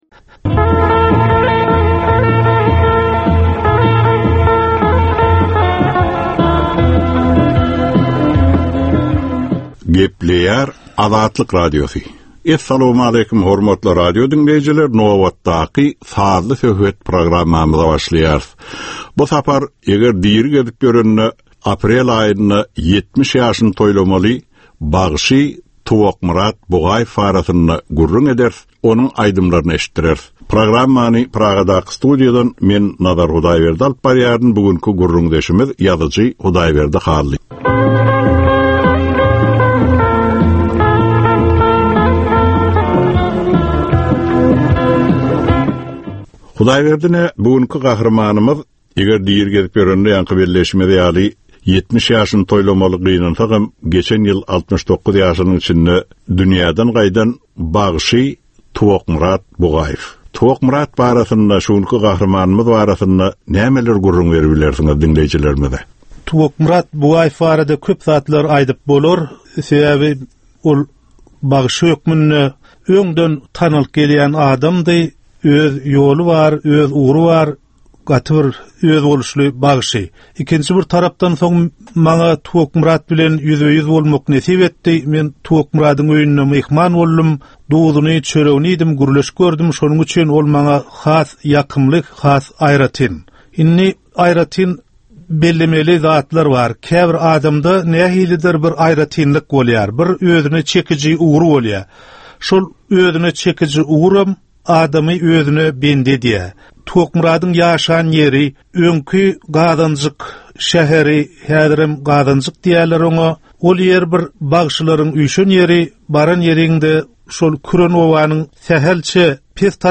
Türkmeniň käbir aktual meseleleri barada sazly-informasion programma.